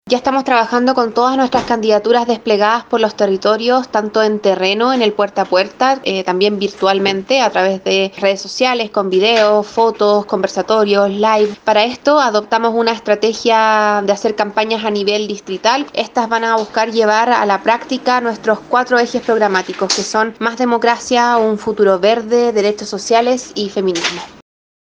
La presidenta de Revolución Democrática, Catalina Pérez, explicó que trabajarán de manera virtual y presencial, con una estrategia de campaña distrital y bajo cuatro objetivos transversales.